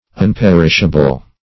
unperishable - definition of unperishable - synonyms, pronunciation, spelling from Free Dictionary
Unperishable \Un*per"ish*a*ble\, a.